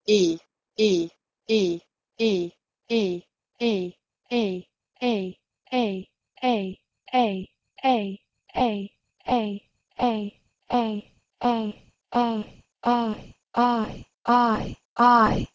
E.g.4. In Middle English, "I" had a close, front vowel, but in mod. English it has lowered and become a diphthong, starting quite open but still close in its latter part.
ii-to-ai.wav